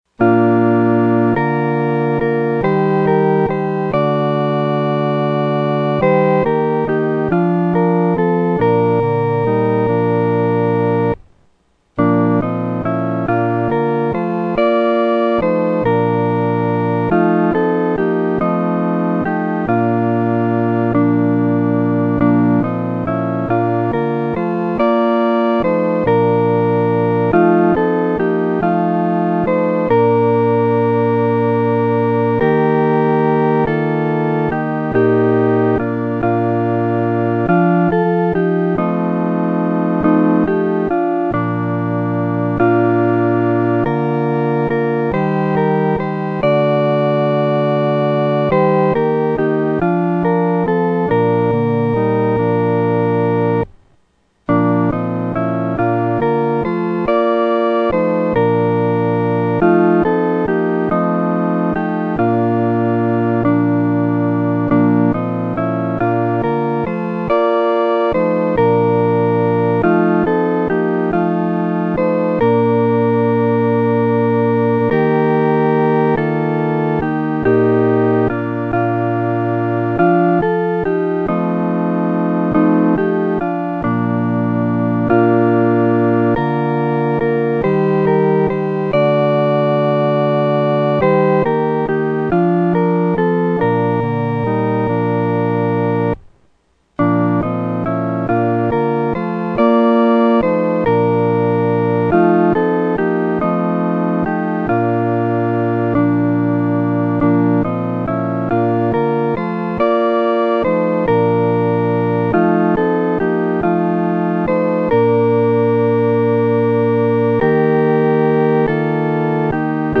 合奏（四声部）
天父必看顾你-合奏（四声部）.mp3